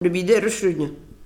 Localisation Saint-Hilaire-des-Loges
Catégorie Locution